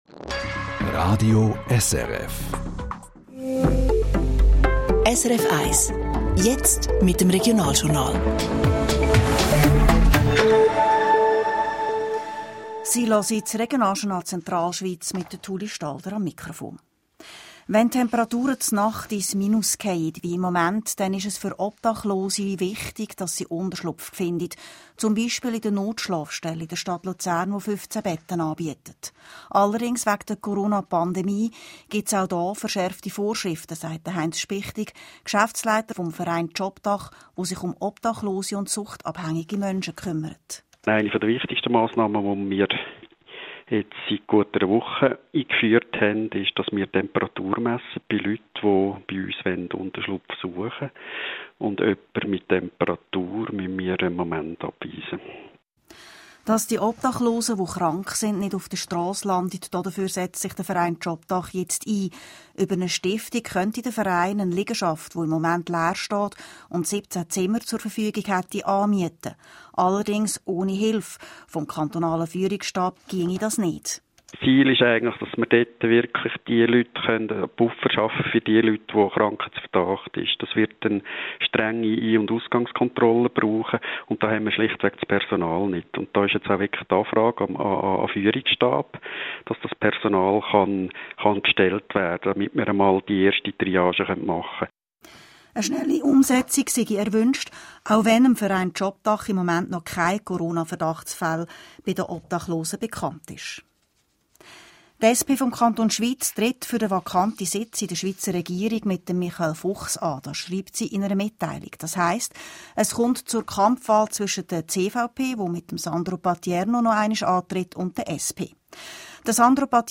25.03.2020 Regionaljournal Zentralschweiz Radiobeitrag